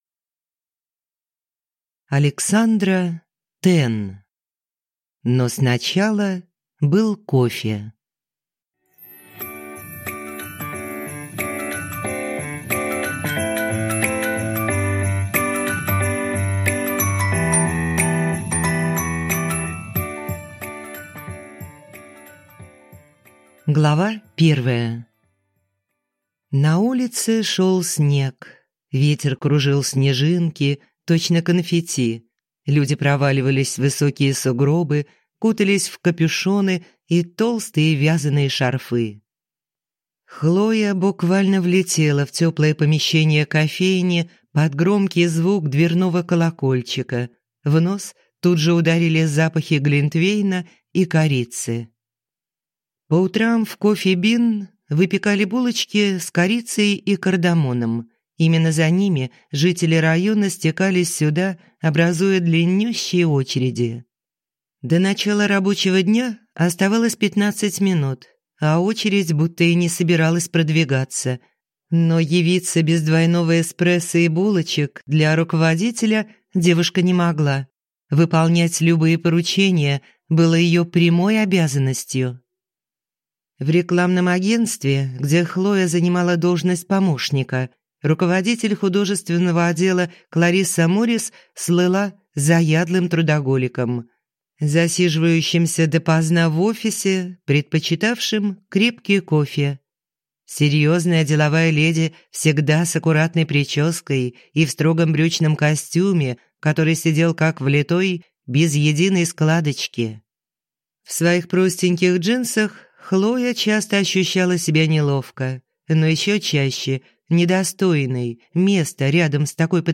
Аудиокнига Но сначала был кофе | Библиотека аудиокниг